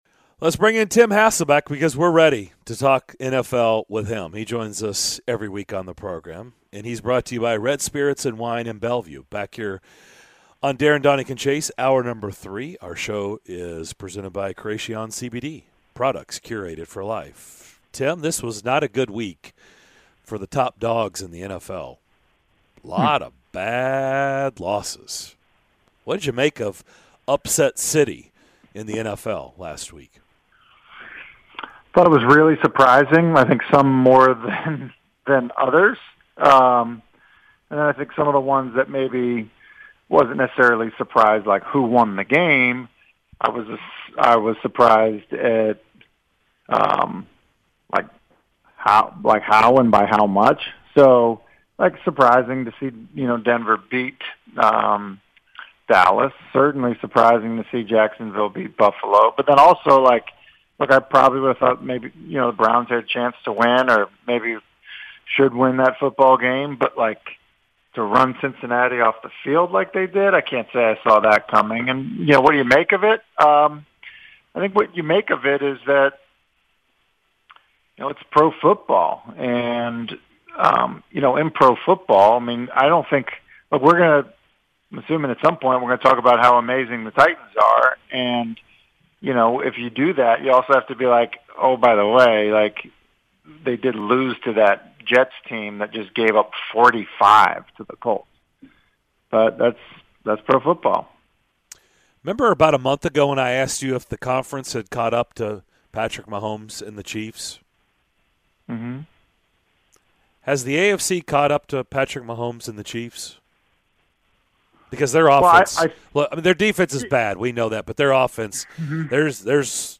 ESPN NFL Analyst Tim Hasselbeck joined the DDC to make sens of the wild weekend that was in the NFL!